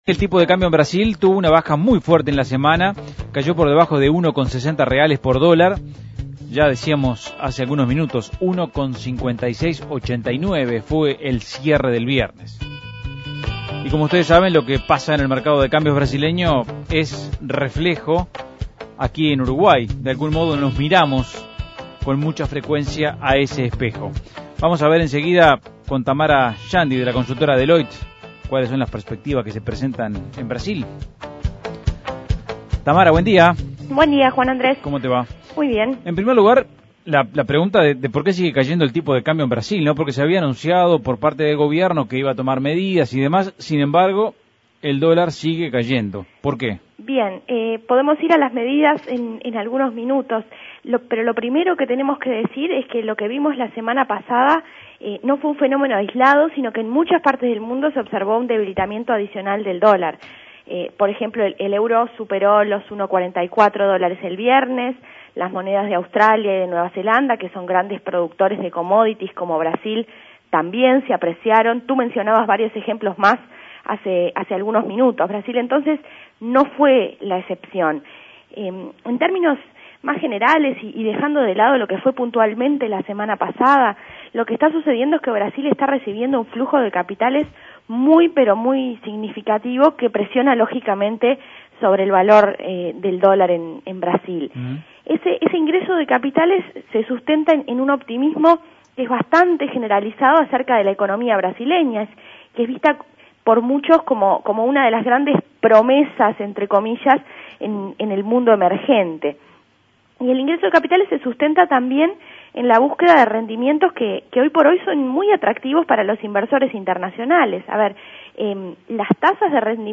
Análisis Económico La caída del tipo de cambio en Brasil